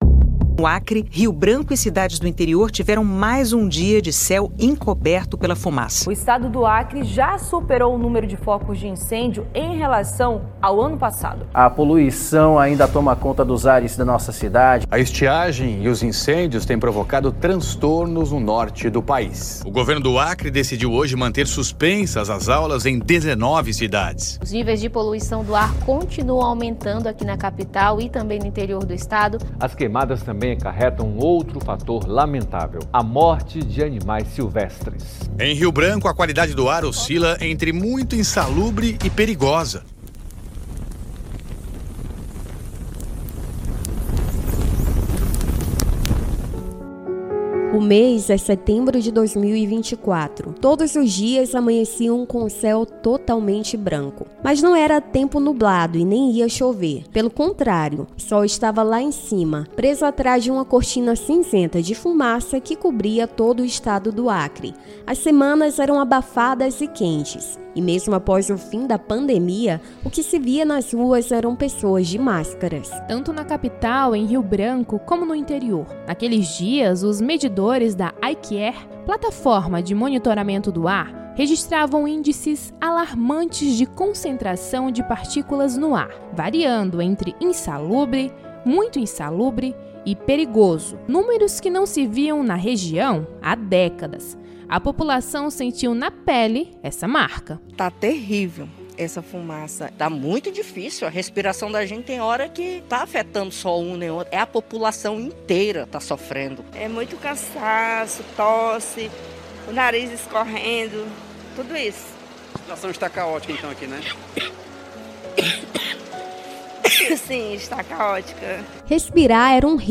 O episódio destaca que, diante de uma das maiores crises climáticas da história, o Acre conseguiu reduzir em mais de 70% os focos de queimadas em apenas um ano. Conversamos com especialistas e autoridades para entender os desafios ainda presentes e reforçar a importância de continuar se conscientizando e agindo para proteger o meio ambiente.